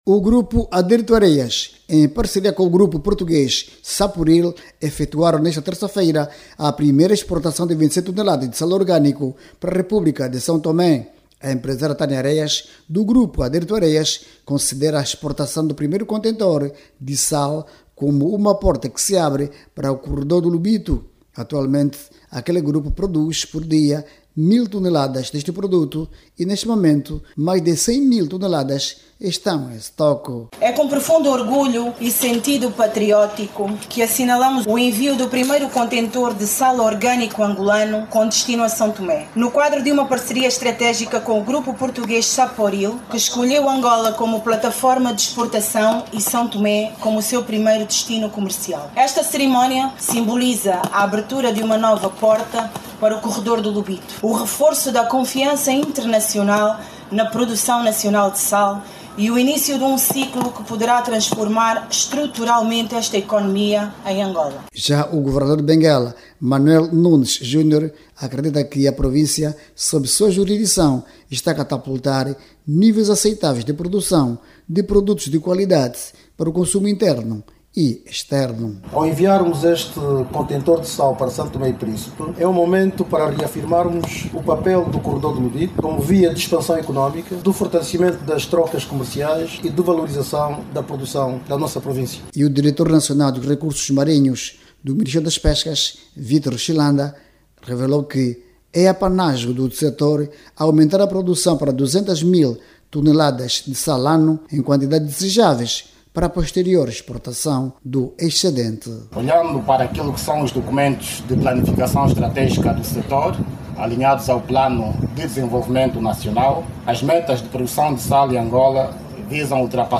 O governante falava durante o acto que assinalou a primeira exportação de sal marinho de Benguela para a República de São Tomé e Príncipe.